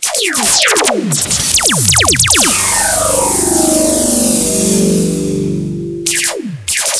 Time Machine-sound-HIingtone